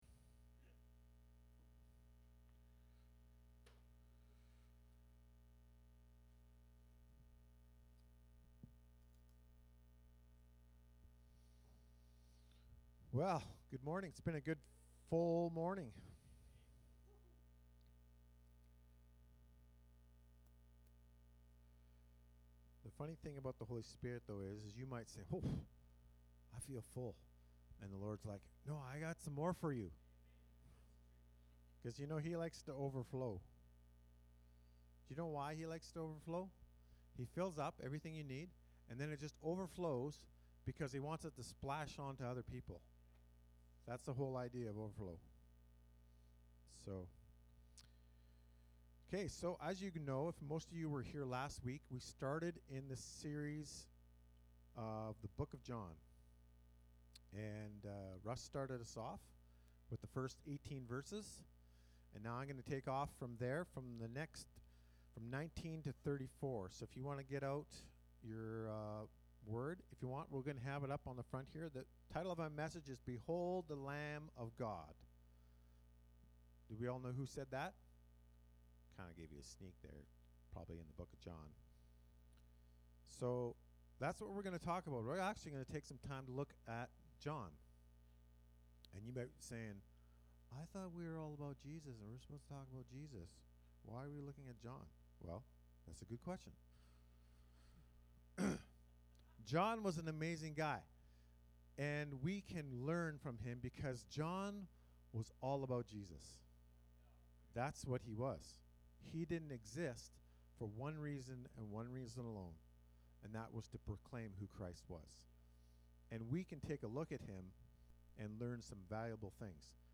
Sermons | Explore Life Church